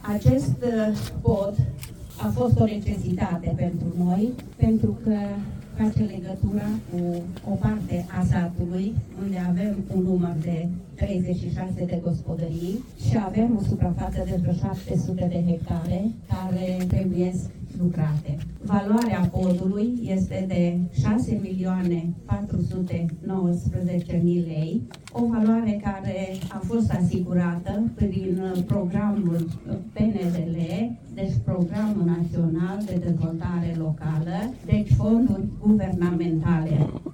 Primarul comunei Crăciunelu de Jos, Lenuța Bubur, a vorbit despre importanța investiției.